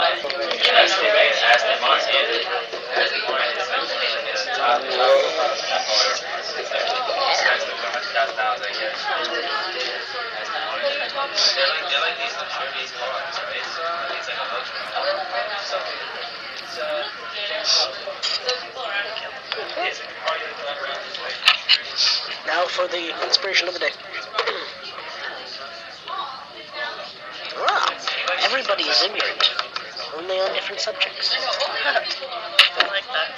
People talking, Bottle being opened, silverware being dropped